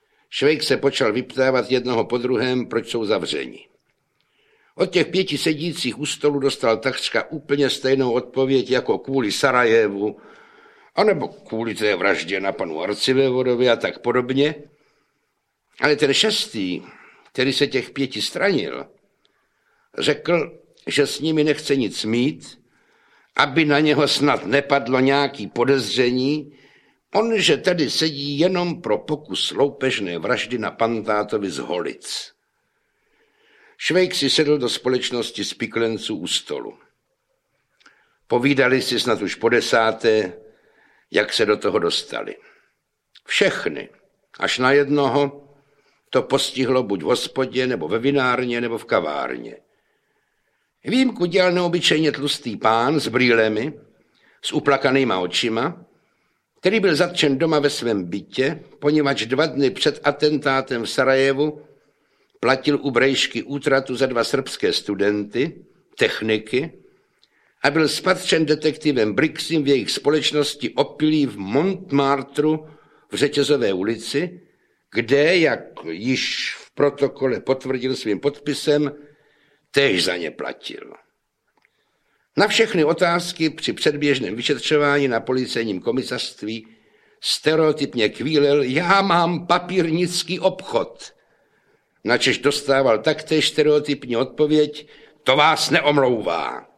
Osudy dobrého vojáka Švejka I. audiokniha
Ukázka z knihy